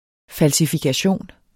Udtale [ falsifigaˈɕoˀn ]